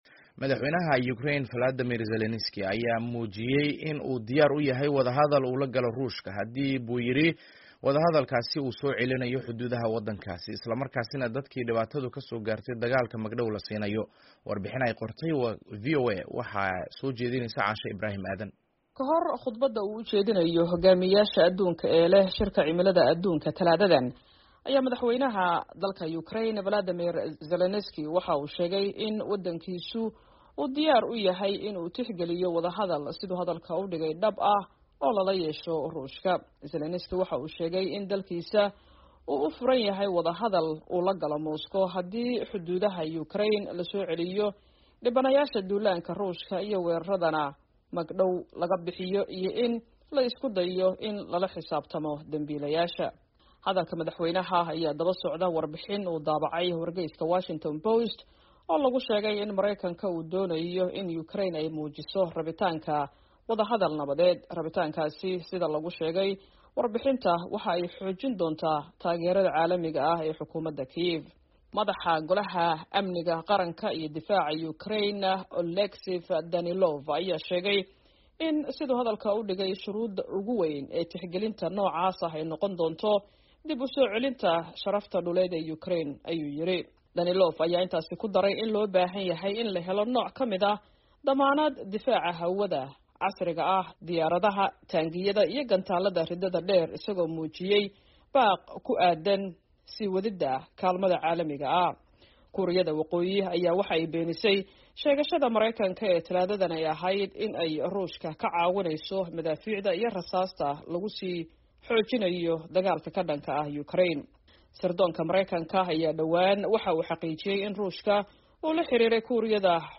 War Deg-Deg ah